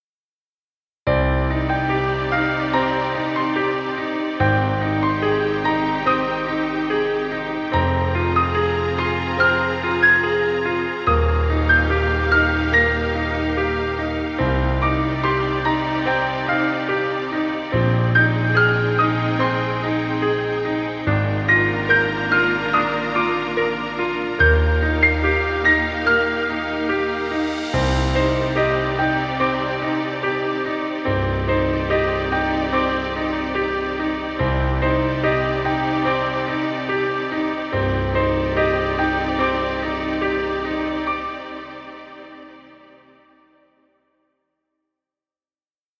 Sentimental cinematic music.. Background music Royalty Free.